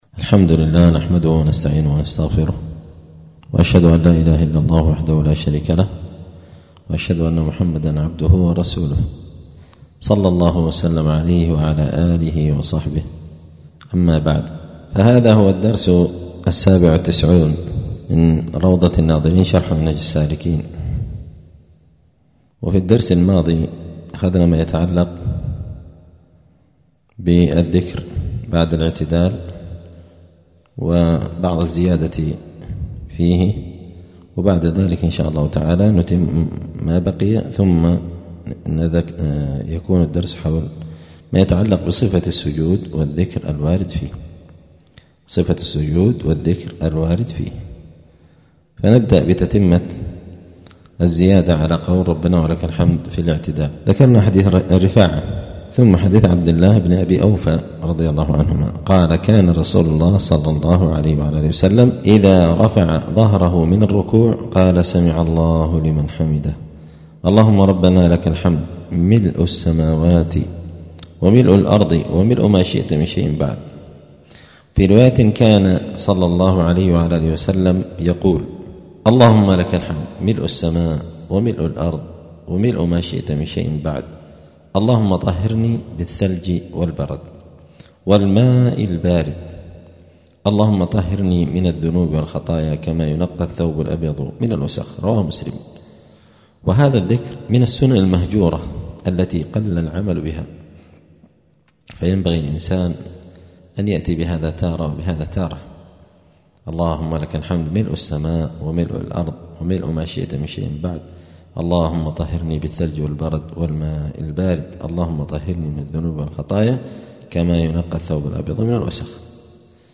الأربعاء 22 رجب 1446 هــــ | الدروس، دروس الفقة و اصوله، كتاب روضة الناظرين شرح منهج السالكين | شارك بتعليقك | 37 المشاهدات